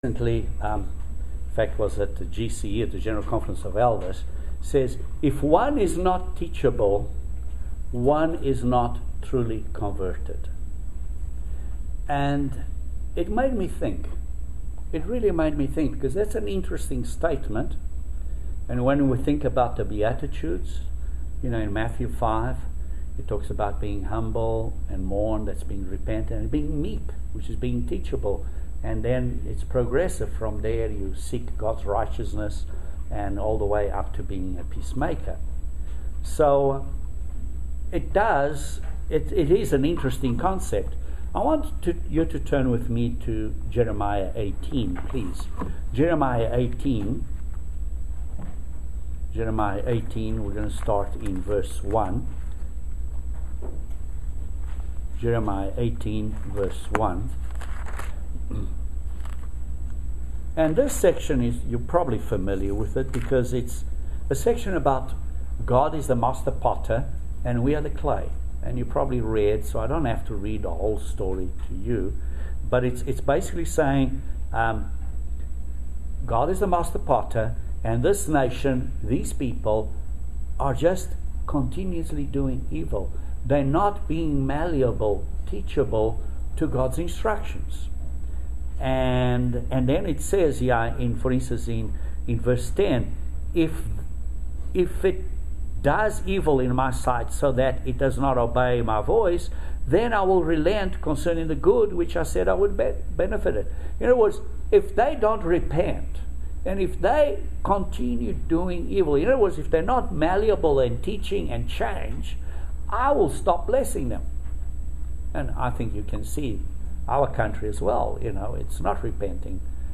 The answers to these questions and more in this amazing video sermon.